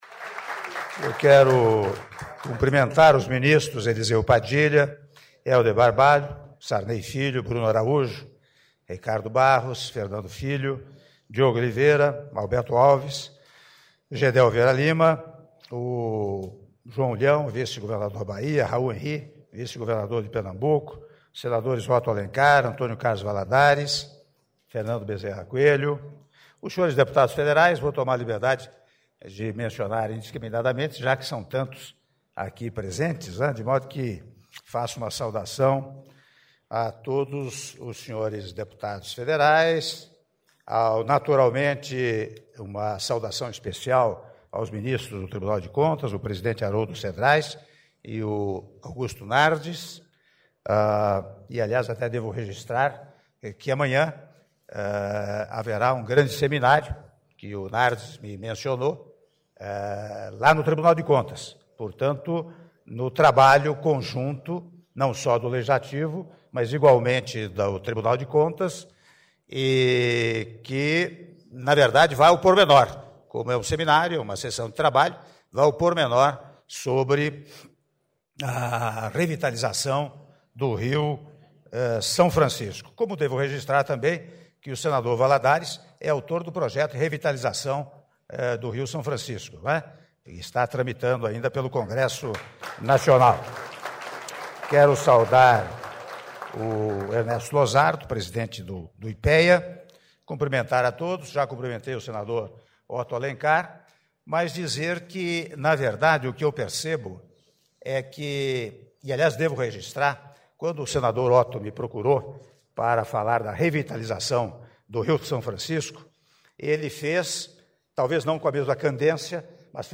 Áudio do discurso do Senhor Presidente da República em exercício, Michel Temer, durante cerimônia de lançamento do Programa de Revitalização da Bacia Hidrográfica do Rio São Francisco - Brasília/DF (08min05s)